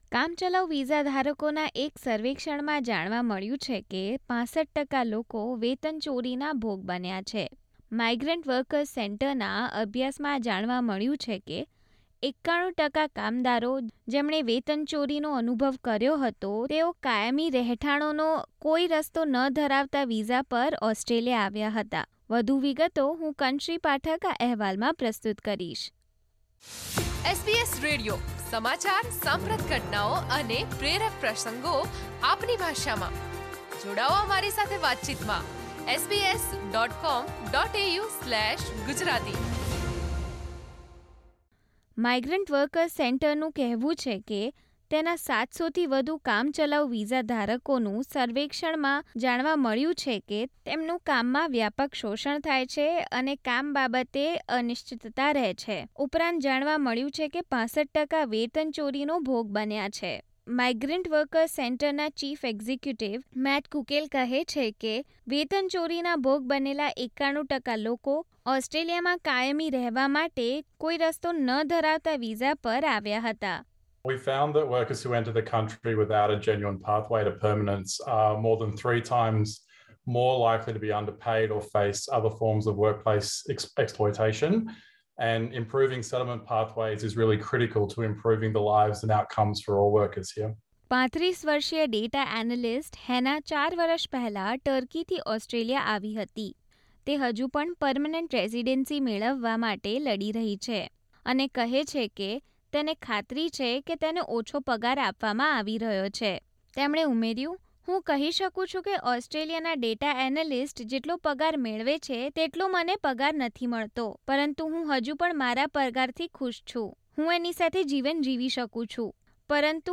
કામચલાઉ વિસાધરકો પર કરવામાં આવેલા એક સર્વે પ્રમાણે, ઓસ્ટ્રેલિયામાં લગભગ 65 ટકા ટેમ્પરરી વિસાધારકો વેતન ચોરીનો ભોગ બને છે. જેમાંથી મોટાભાગના લોકો ઓસ્ટ્રેલિયામાં પર્મેનન્ટ રેસીડન્સીની તક ન હોય તેવા વિસા પર ઓસ્ટ્રેલિયા આવ્યા છે. વધુ વિગતો મેળવીએ અહેવાલમાં.